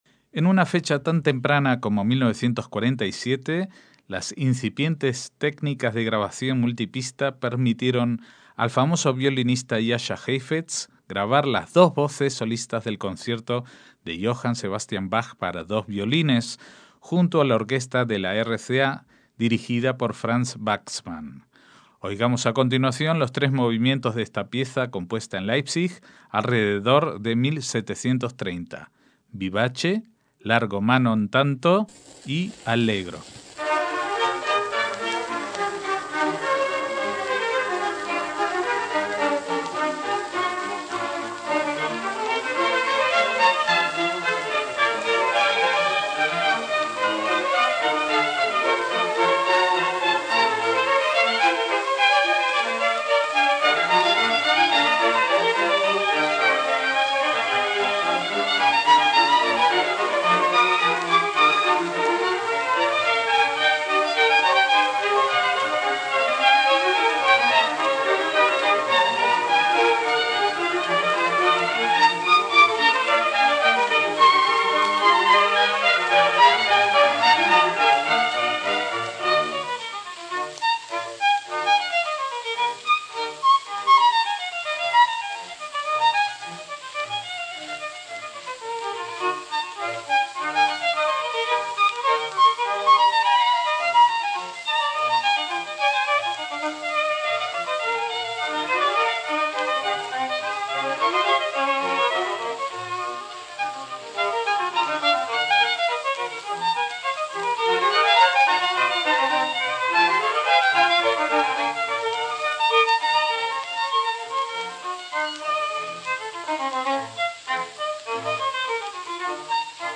MÚSICA CLÁSICA
para dos violines y orquesta